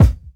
Boom-Bap Kick 93.wav